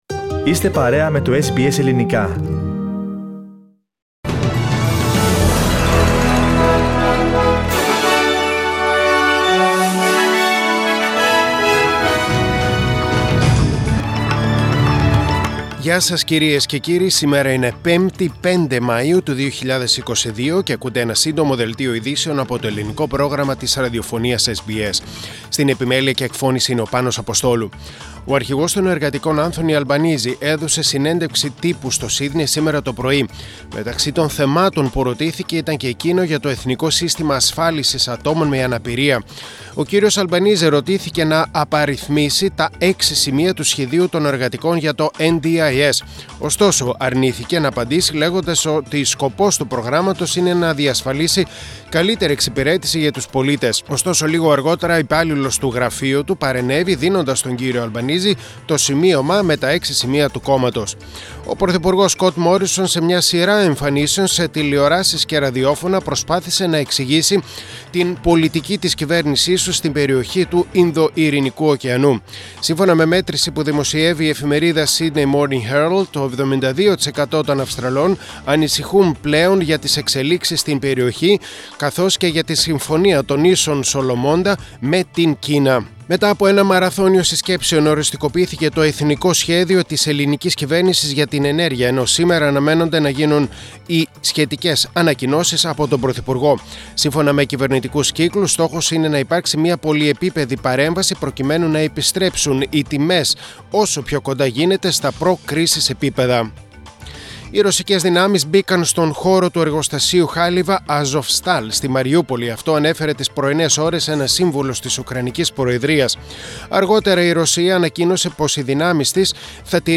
Σύντομο Δελτίο ειδήσεων στα Ελληνικά.